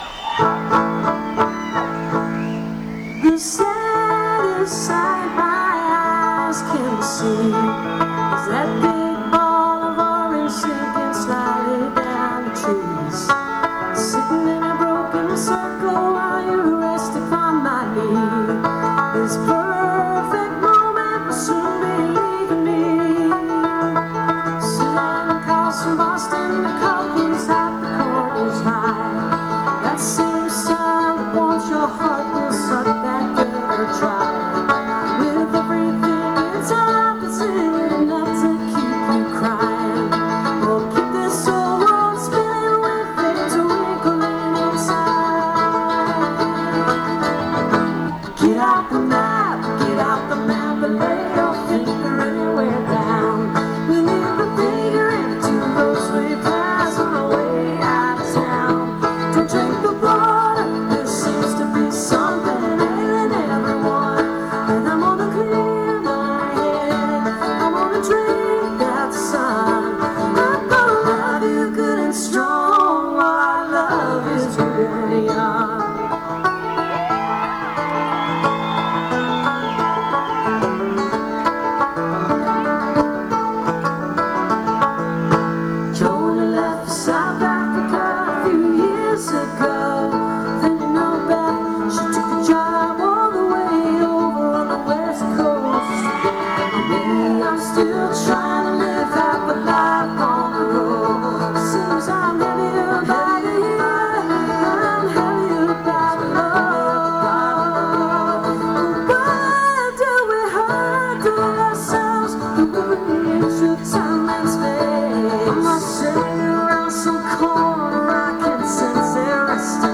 (acoustic show)